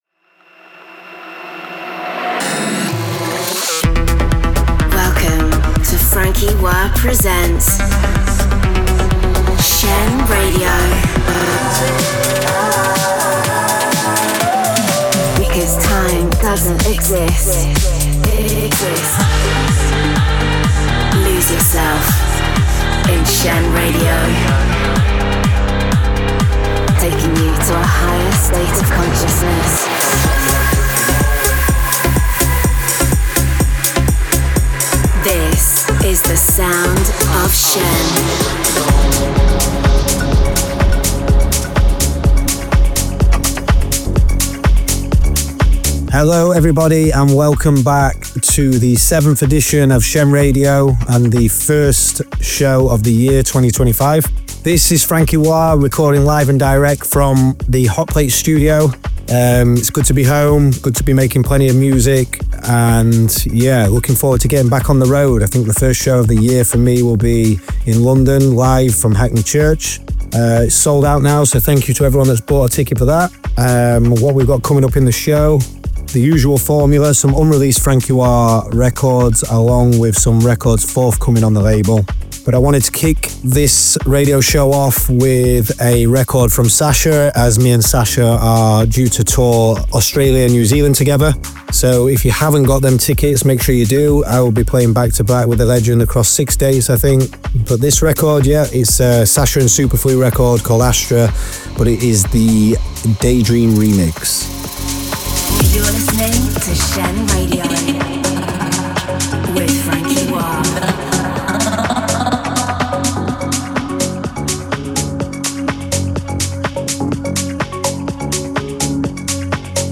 conceptual, cutting-edge, progressive sounds